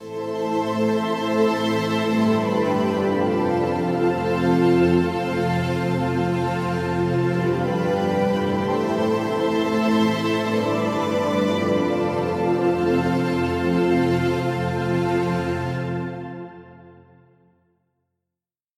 混合纹理合成器 Karanyi Sounds Polyscape 2 Pro KONTAKT-音频fun
每个声音库都有不同的风格和特点，涵盖了从 80 年代复古到现代电子的各种流派。
这个扩展包专注于展示库的其他用途：史诗般的键盘、饱满而梦幻的垫音、电影般的低音，甚至一些不和谐的特效声音。